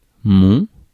Ääntäminen
IPA: [mɔ̃]